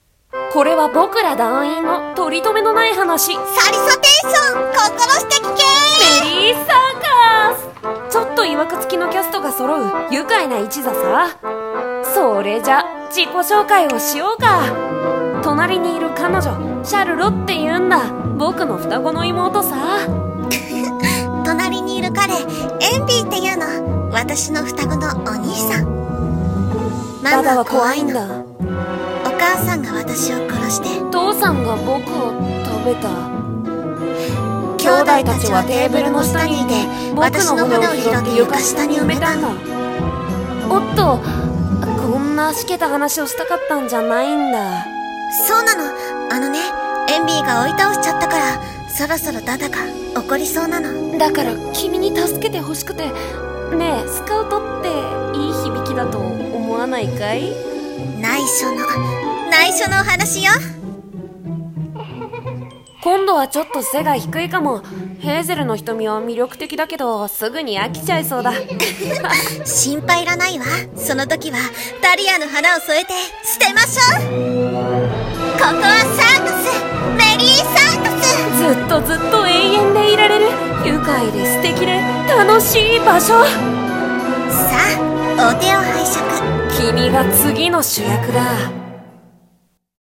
CM風声劇「メリー・サーカス